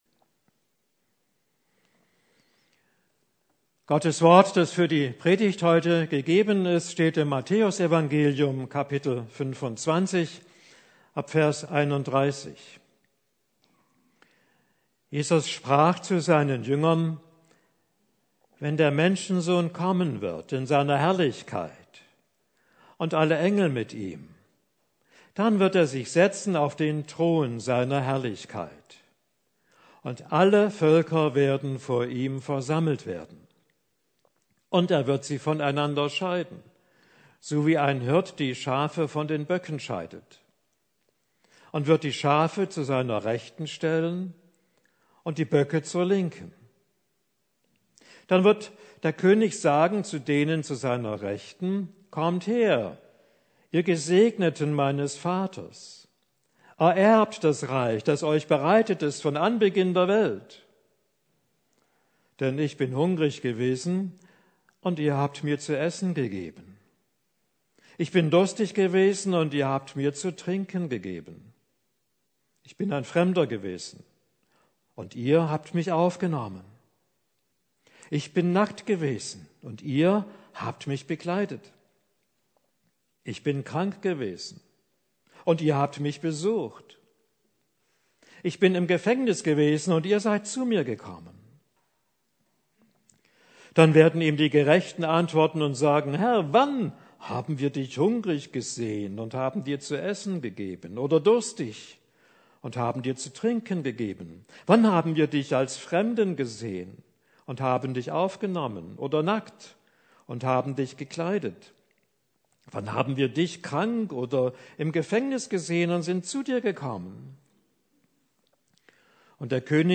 Predigt für den Vorletzten Sonntag des Kirchenjahres (2023)